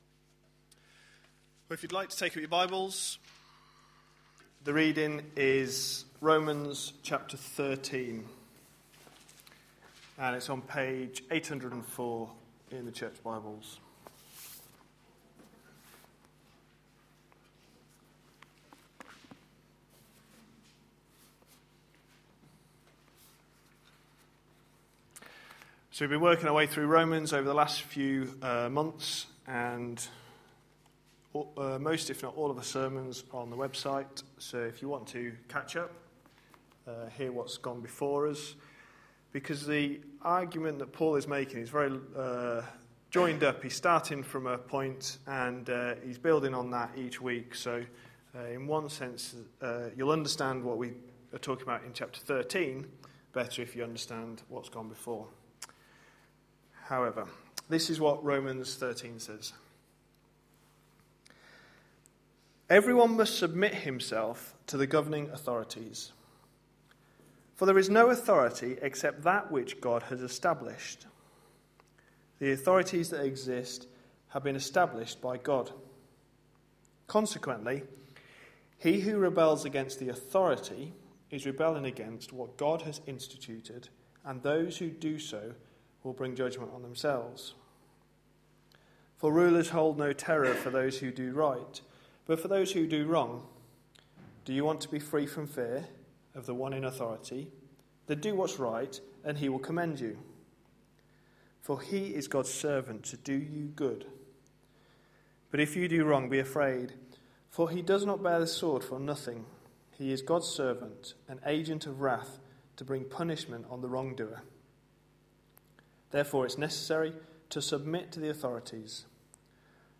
A sermon preached on 10th March, 2013, as part of our Romans series.